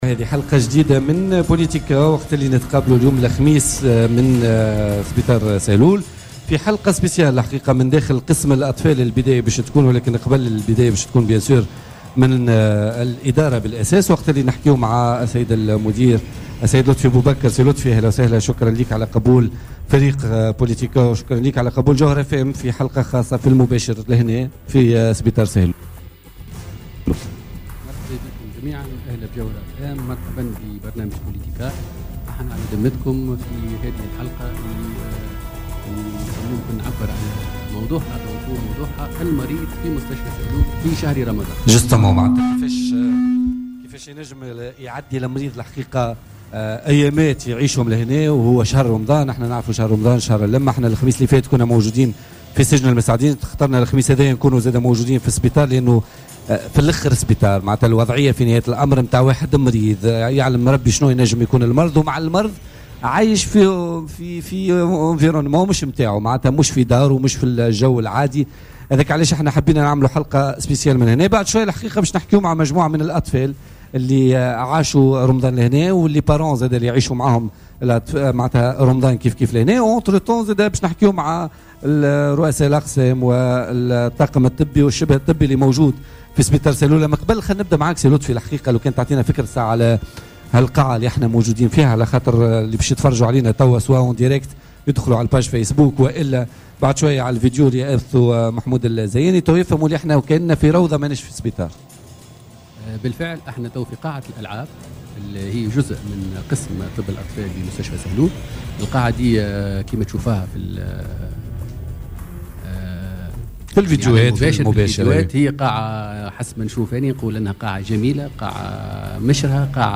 "بوليتيكا" في بث مباشر من مستشفى سهلول